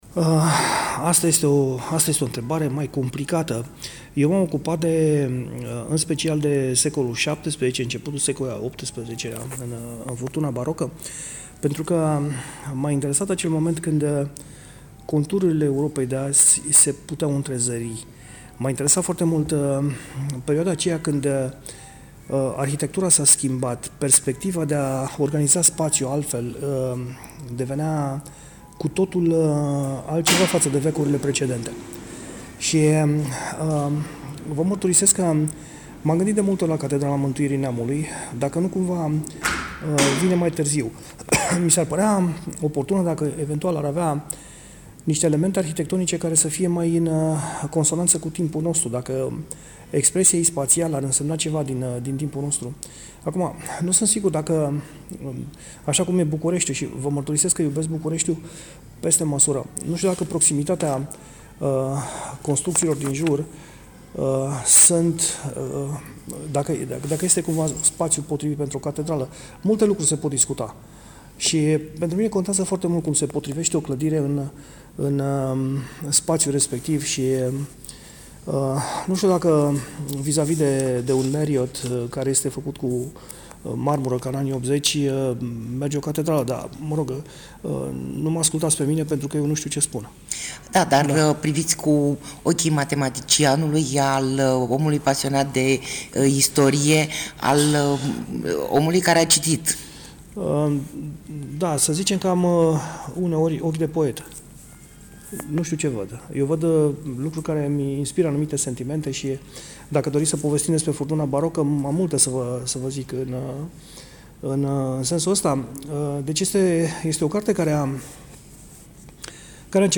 Interviu-Suceava-HIT.mp3